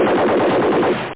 Amiga 8-bit Sampled Voice
mgun.mp3